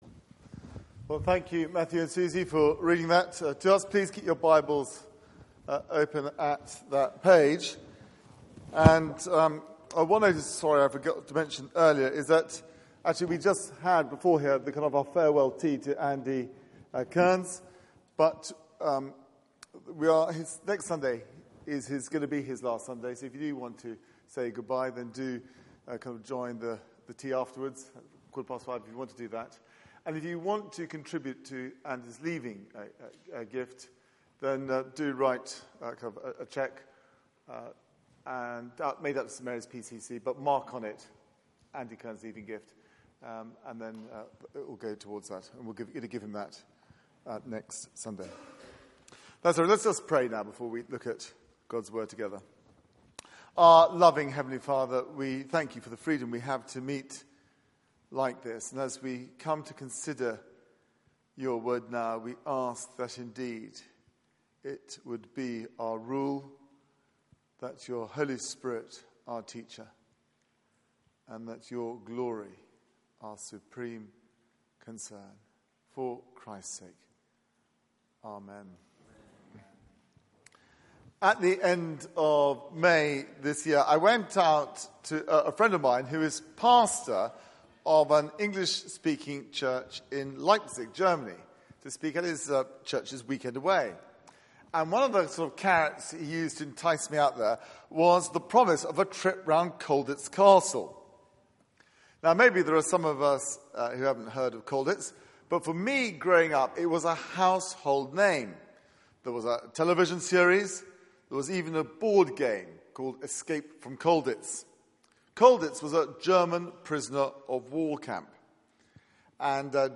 Passage: Exodus 13:17-14:31 Service Type: Weekly Service at 4pm